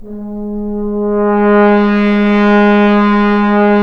Index of /90_sSampleCDs/Roland L-CD702/VOL-2/BRS_Accent-Swell/BRS_FHns Swells